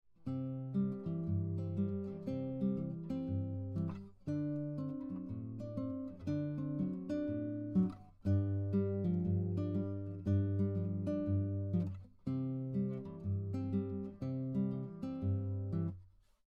This fingerpicking style is characterized by a repeated bass pattern, while playing chords and melody at the same time.
Here is another picking pattern using an arpeggio variation for each chord. Another thing I want to mention is how the rhythms repeat every two measures, which create a sequence in the music.
Travis picking pattern 6 arpeggio